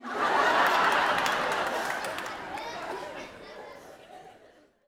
Audience Laughing-09.wav